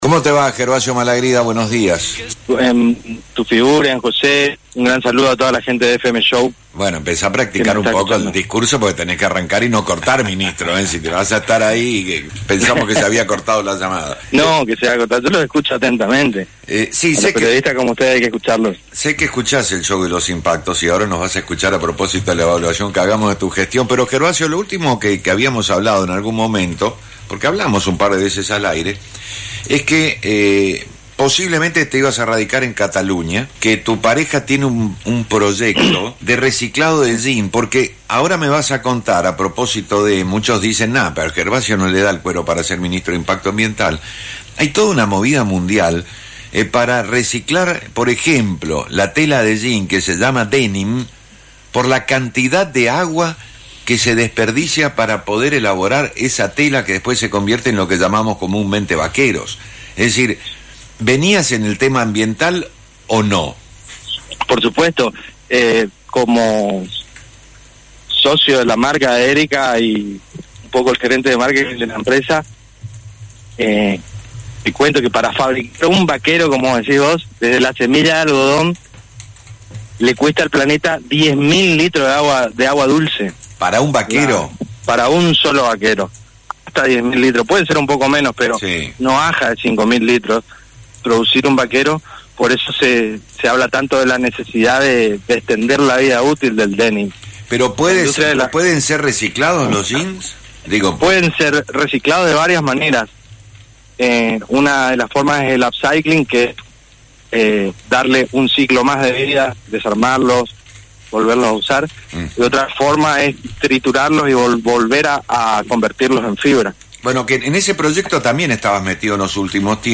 El nombramiento de Gervasio Malagrida como titular del Ministerio de Cambio Climático resonó en las líneas políticas y en la radio FM Show explicó cómo piensa trabajar en el ámbito que le toca.